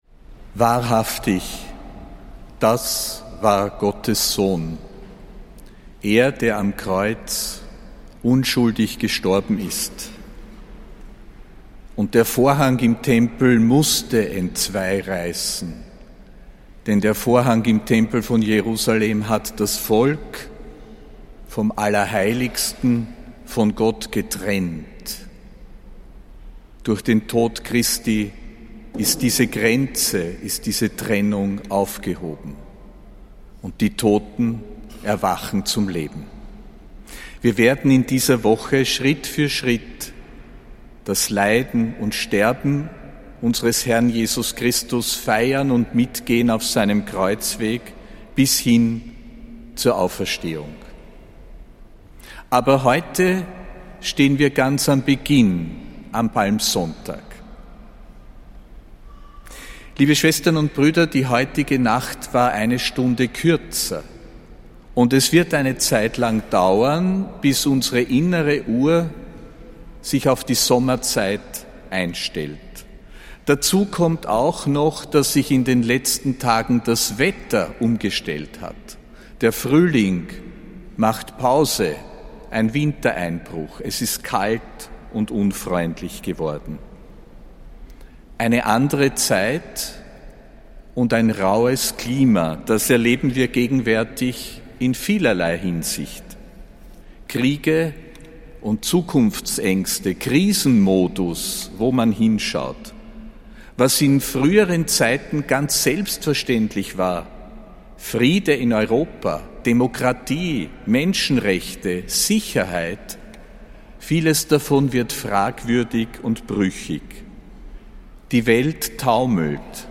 Predigt von Erzbischof Josef Grünwidl zum Palmsonntag, am 29. März